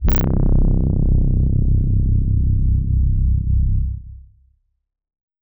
Lush Pad C2.wav